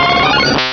Cri de Mimitoss dans Pokémon Rubis et Saphir.
Cri_0048_RS.ogg